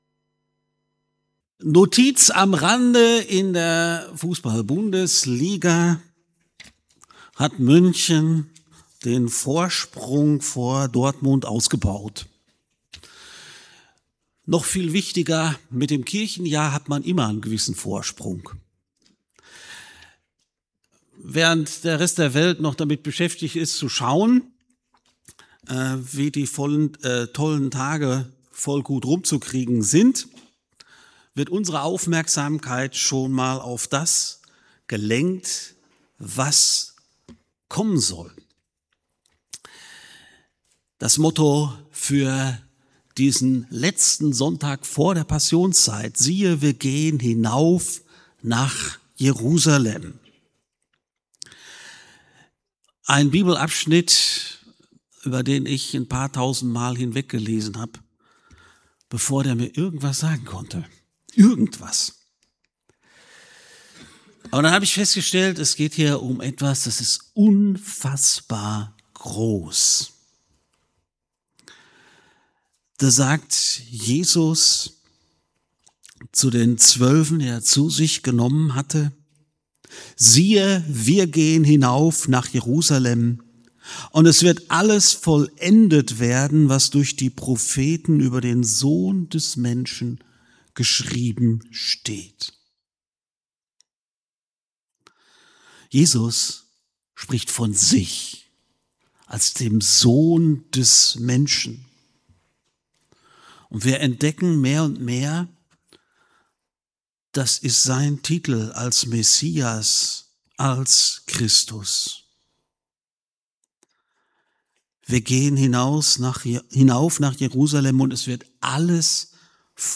FeG Aschaffenburg - Predigt